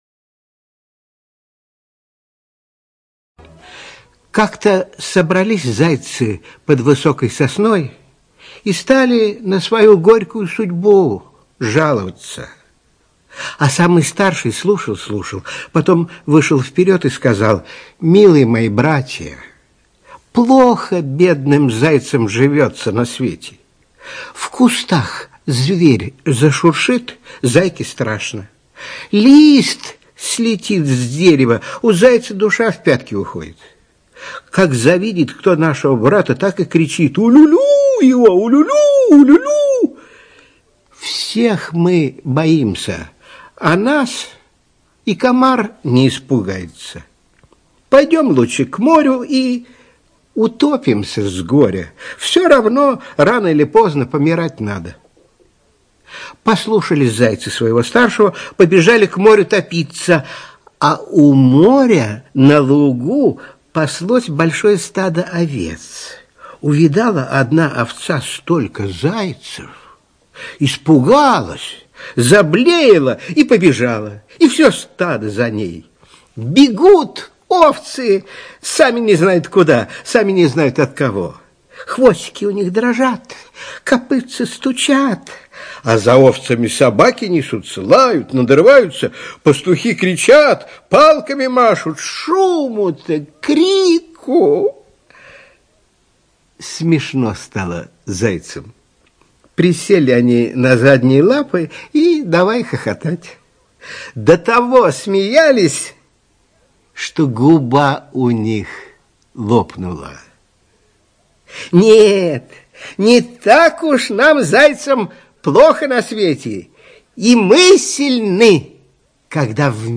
ЧитаетЛитвинов Н.
ЖанрСказки